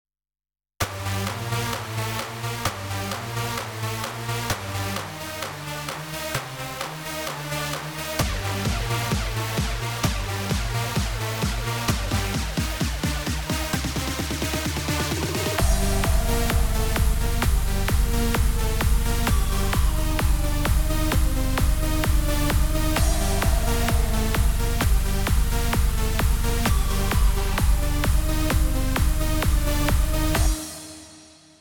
קצת משעמם זה לא מספיק מלא אבל זה דרופ של החיים
סאונד נקי כמו שצריך!
חזק ביותר מאוד אגרסיבי